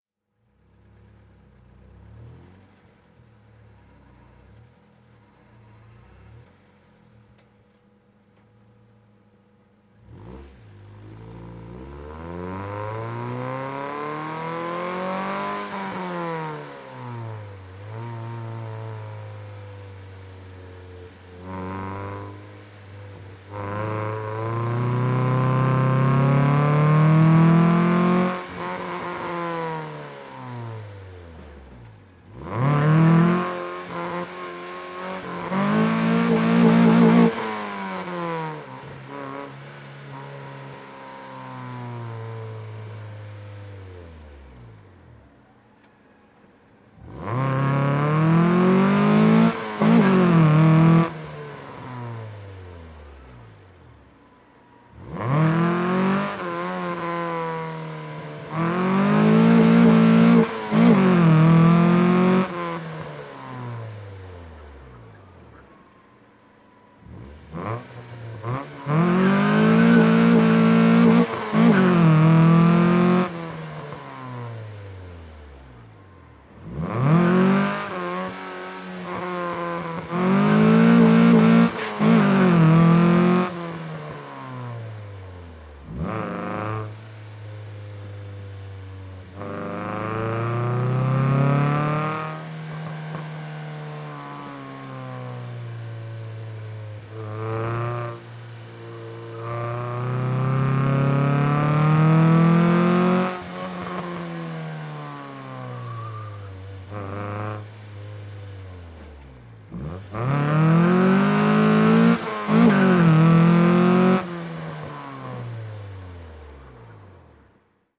Integra onboard - engine, fast accelerates & corners, revs